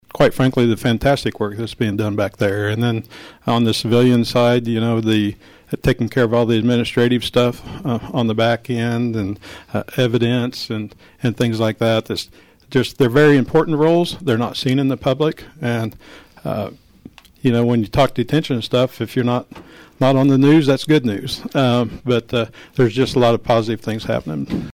Lyon County Sheriff Jeff Cope says this new program is not only rewarding to those within their agency, but it has also been enlightening to him and his fellow leadership, saying he learned a lot about many of his deputies and officers over the course of the selection process.